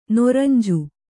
♪ noranju